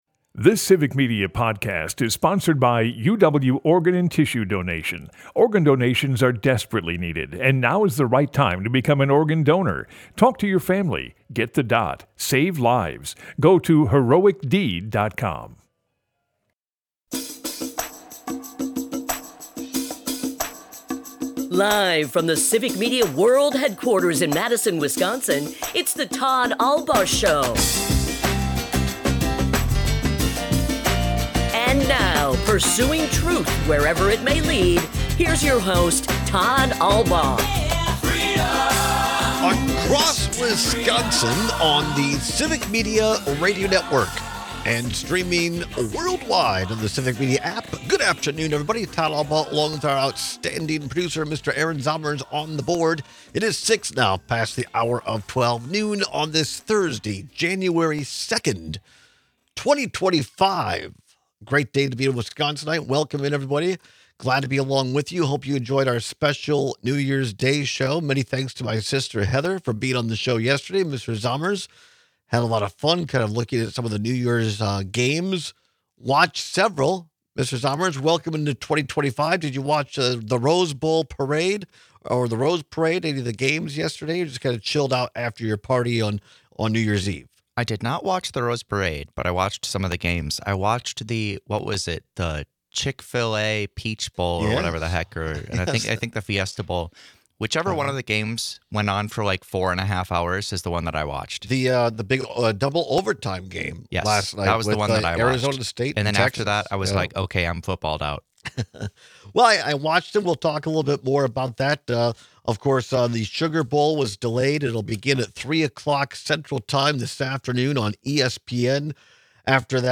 Welcome to our first live show of 2025! Unfortunately, we must begin the year by covering some tragic events from the last two days.